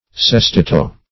sestetto - definition of sestetto - synonyms, pronunciation, spelling from Free Dictionary Search Result for " sestetto" : The Collaborative International Dictionary of English v.0.48: Sestetto \Ses*tet"to\, n.[It.]